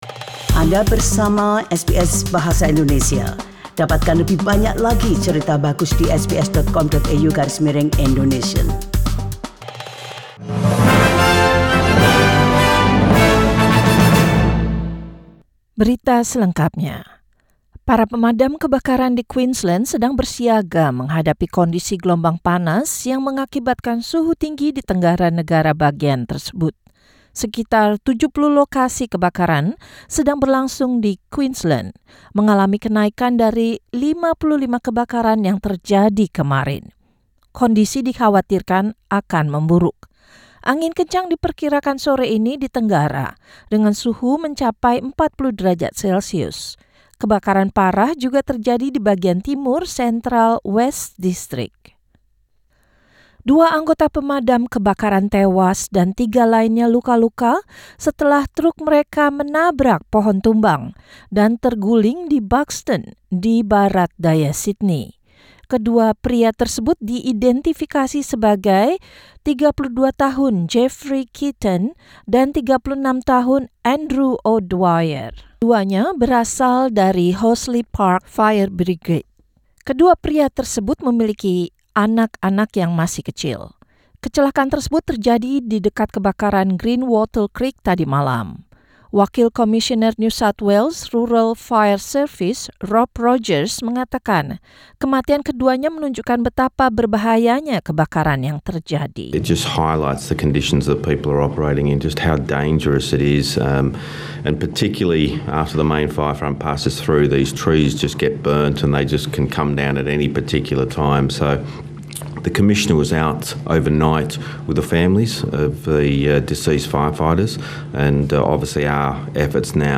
News in Indonesian, Friday 20 December 2019.